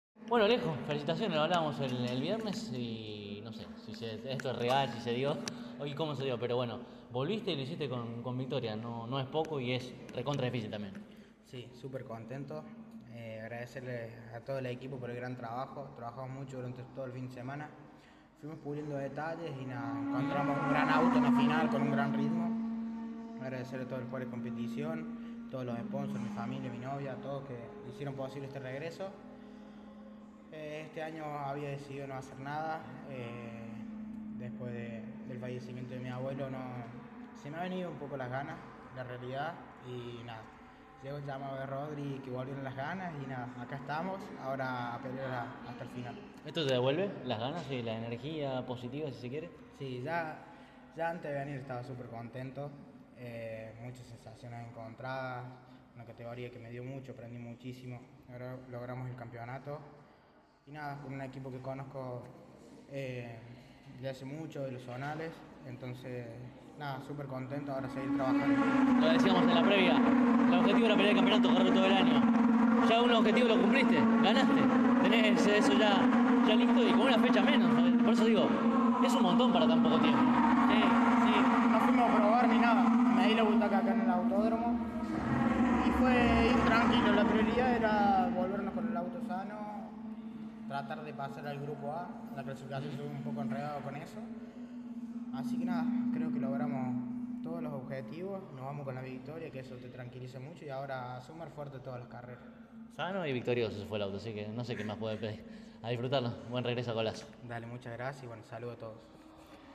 Todos ellos dialogaron con CÓRDOBA COMPETICIÓN, y aquí debajo, en orden, podés oir sus voces: